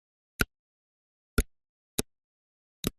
3. Звук клешней рака